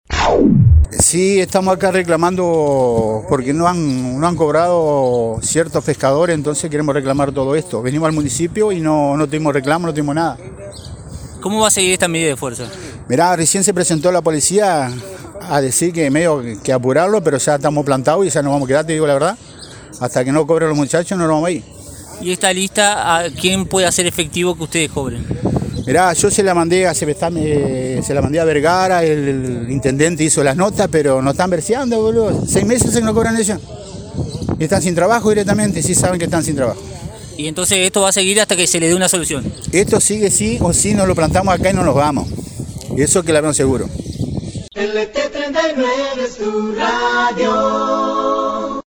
“Recién se presentó la policía medio a apurarnos, pero hasta que no cobren los muchachos no nos vamos a ir”, declaró a este medio uno de los manifestantes y agregó: “nos están ‘versiando’, hace mas de 6 meses que no cobran”